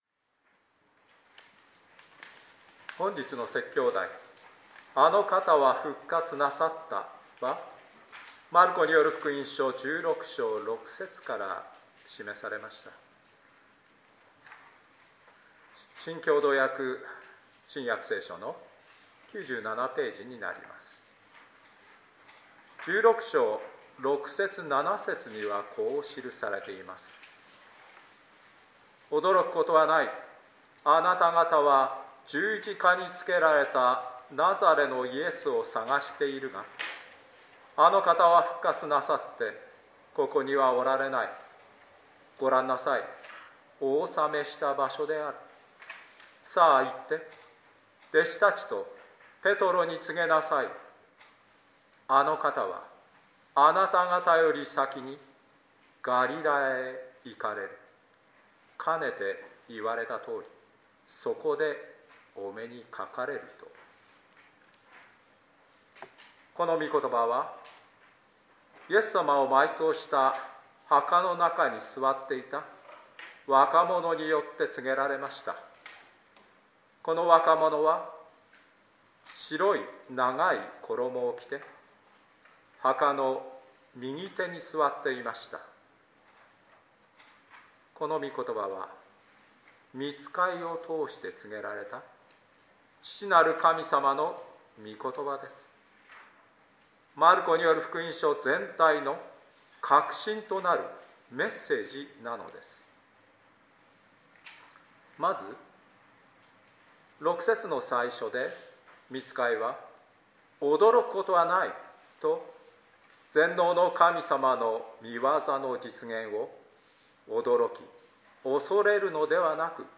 あの方は復活なさった（イースター礼拝（復活日） 2021.4.4 説教録音など）